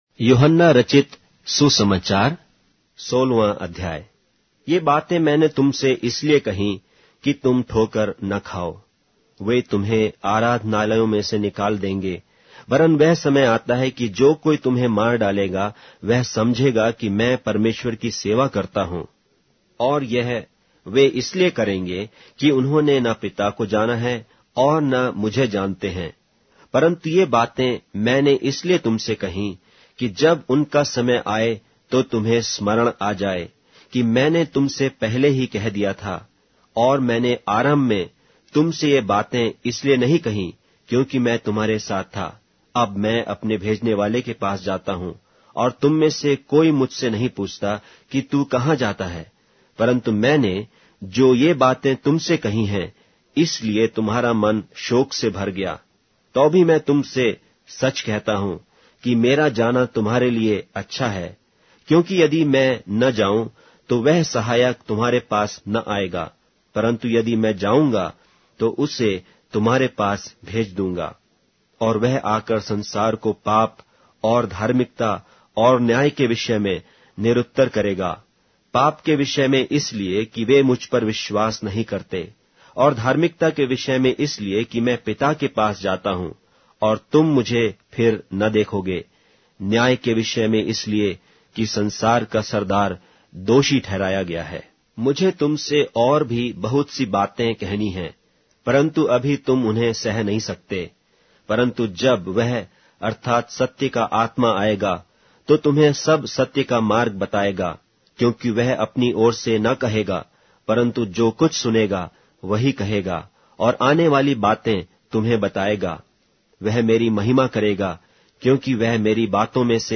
Hindi Audio Bible - John 3 in Ecta bible version